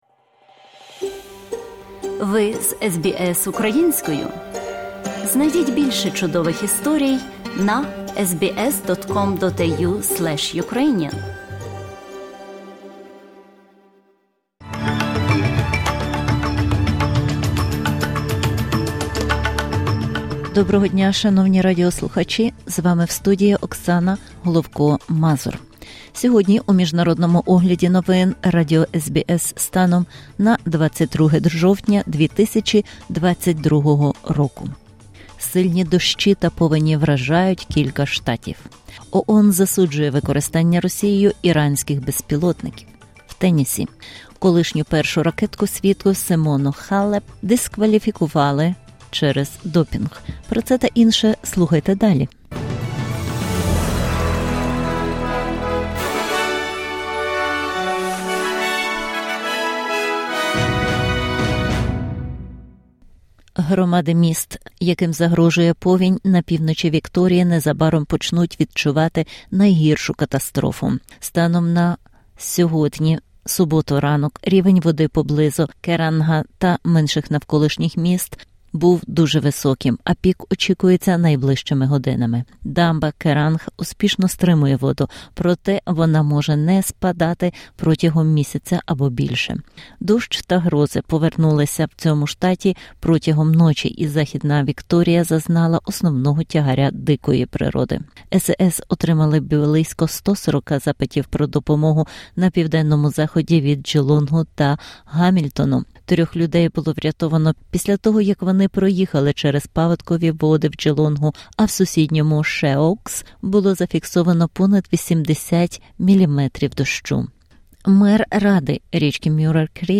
SBS news in Ukrainian - 22/10/2022